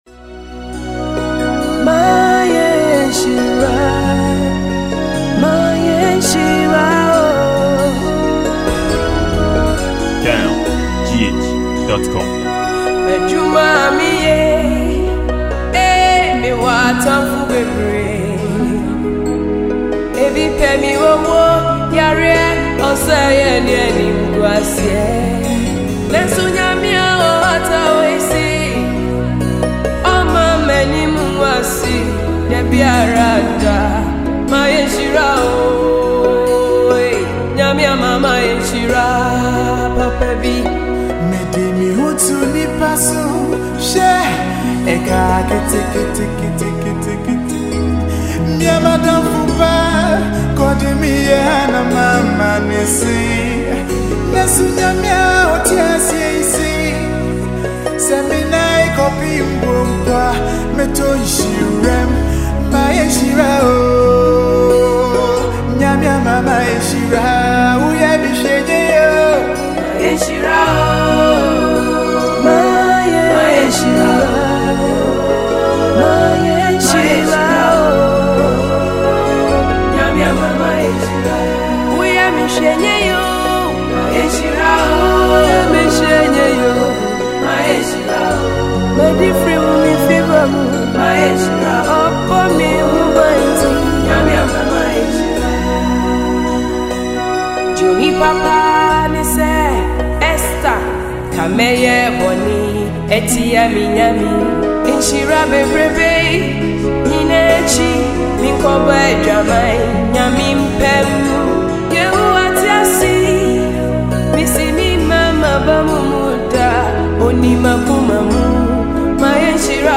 Ghanaian gospe; singer